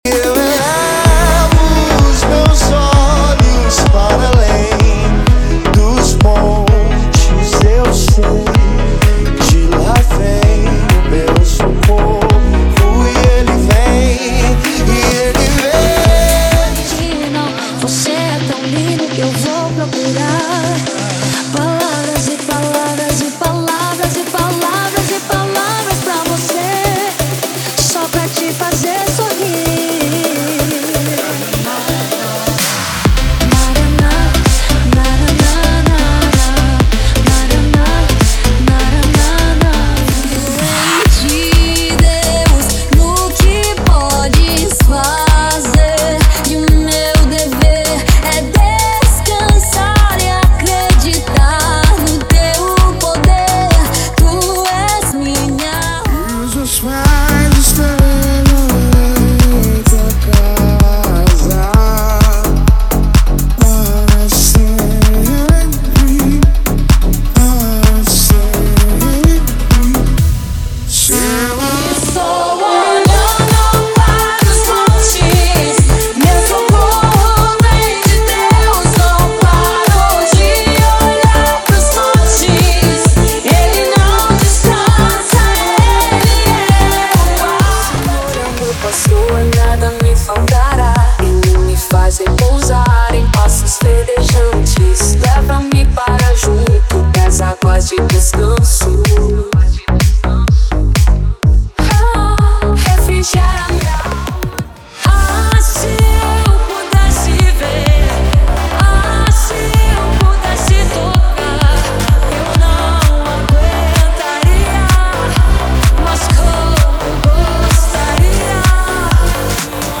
Atmosfera intensa de adoração com remixes estendidos.
Batidas dançantes e mensagens edificantes.
O ritmo do funk com letras cristãs e consciência.
Remixes internacionais com sonoridade global.
Adoração e beats eletrônicos com qualidade profissional.
Reggae cristão com vibração positiva e espiritualidade.
• Sem Vinhetas